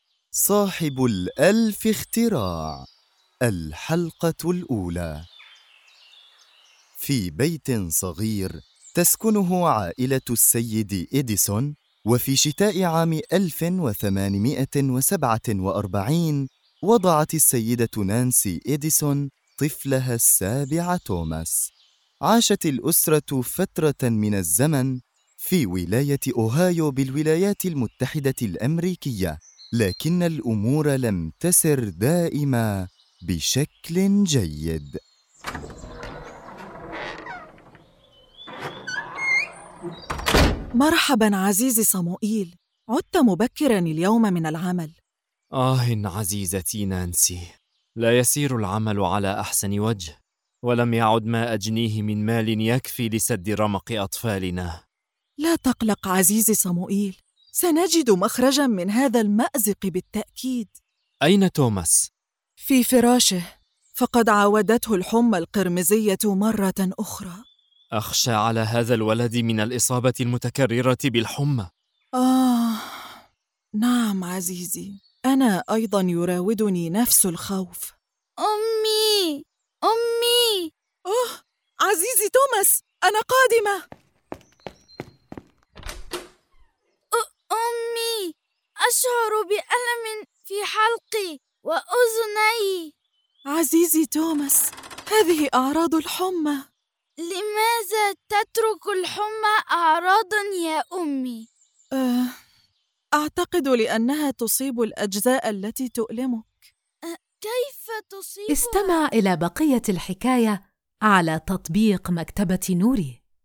كل قصة يتم تقديمها بصوت جذاب جميل، مع مؤثرات لتحفيز الطفل على التفاعل والاندماج في عالم الحكايات.